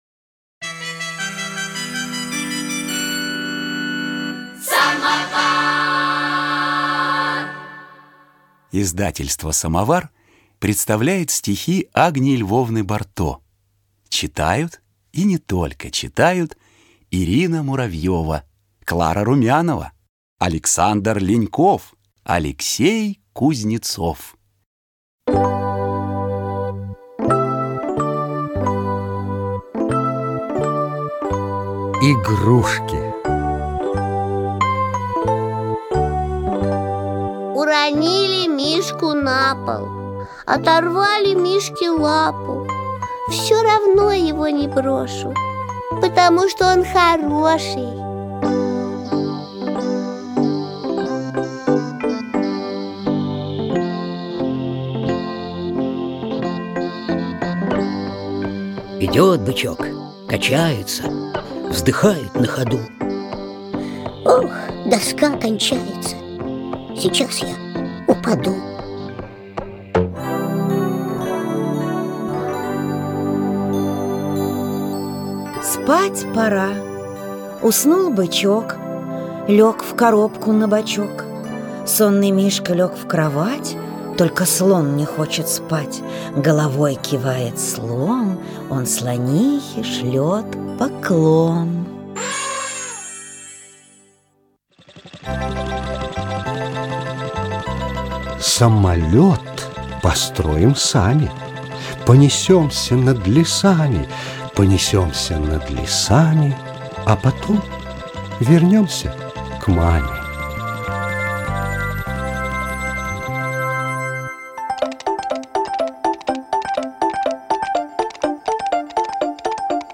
Исполнитель: Студия “Самовар”
Жанр: Детские стихи